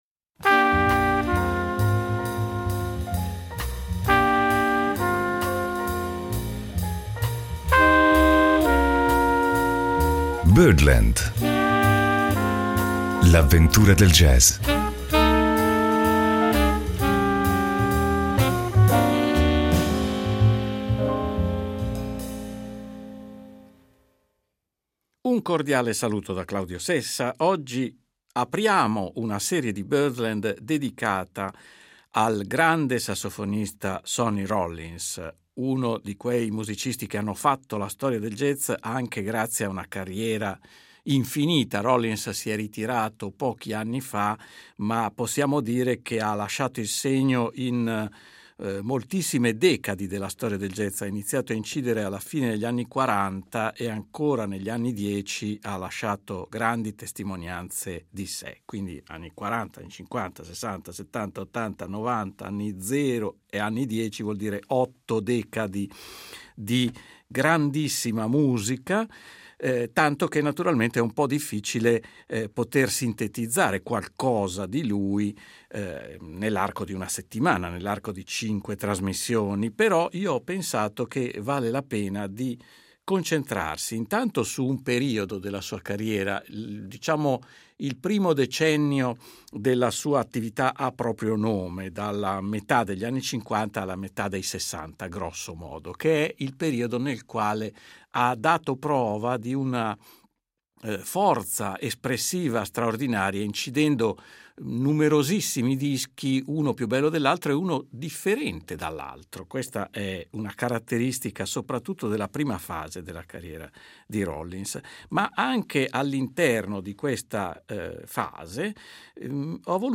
trio sassofono-basso-batteria